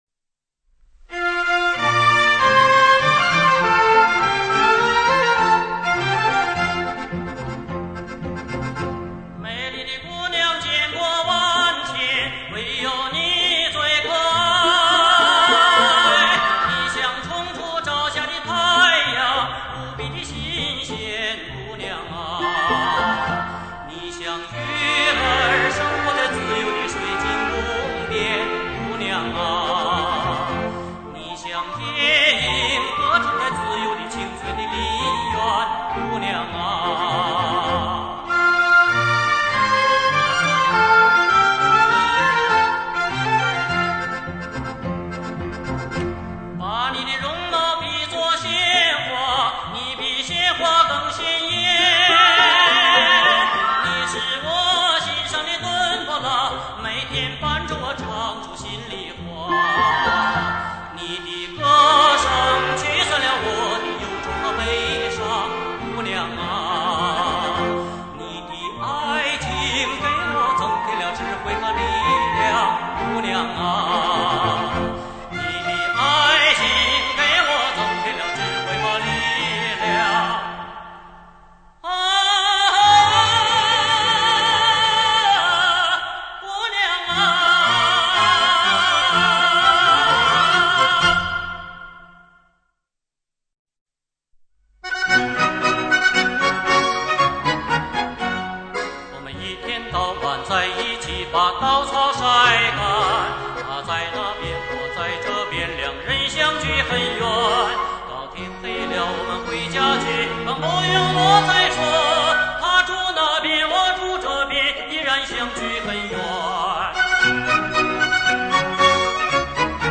风格多样、抒情，旋律优美动听。